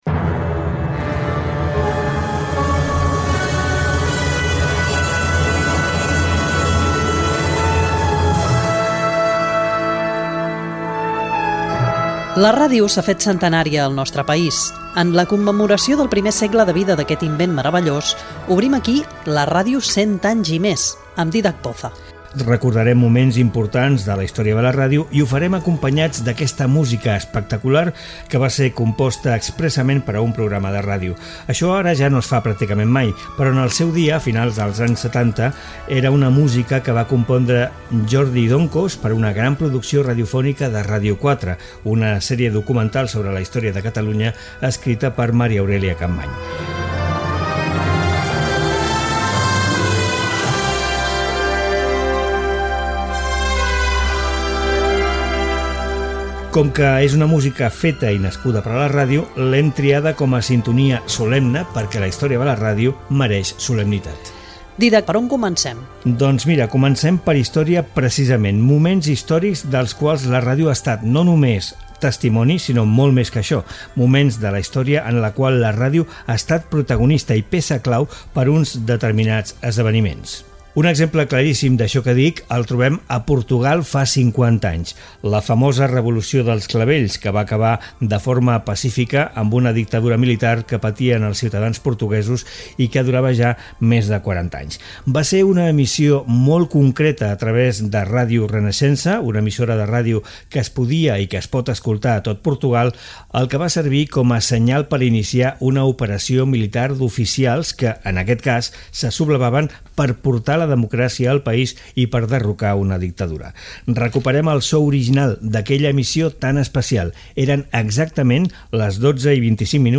Gènere radiofònic Divulgació